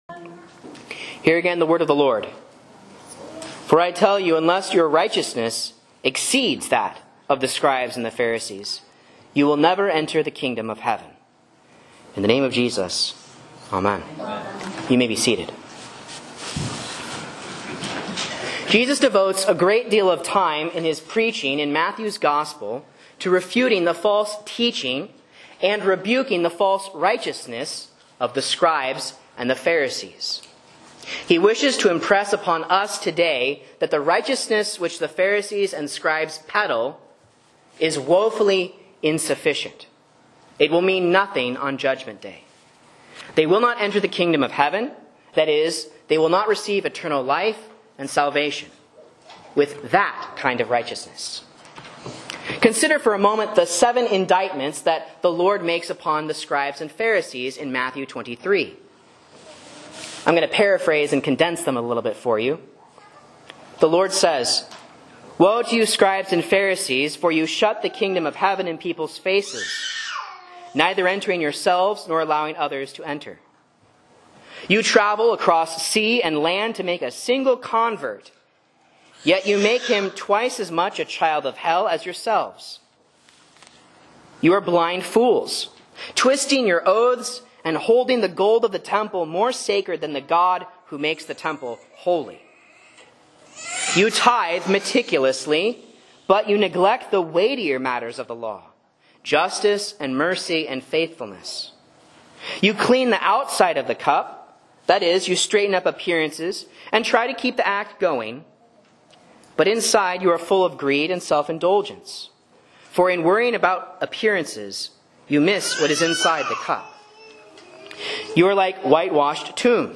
A Sermon on Matthew 5:17-20 for Epiphany 5 (A)